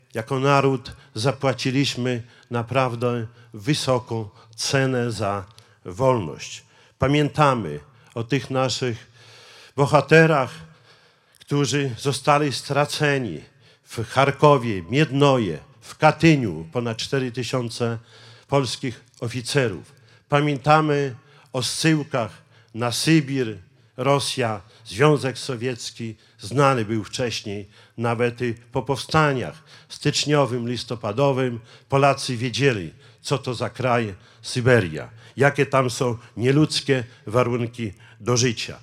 Po mszy świętej w kościele pod wezwaniem świętych Piotra i Pawła głos zabrał Czesław Renkiewicz, prezydent Suwałk. Również przypomniał wydarzenia z 17 września 1939 roku i ich następstwa.